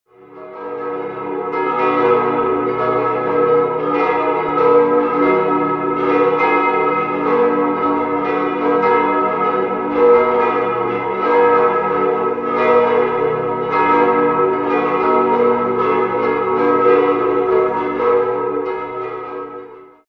6-stimmiges Geläute: h°-dis'-fis'-gis'-h'-eis'' Im Turm hängen insgesamt neun historische Glocken, von denen sechs das Hauptgeläute bilden, dessen Glocken aus den Jahren 1318, 1399, 1405, 1515, 1521 und 1537 stammen.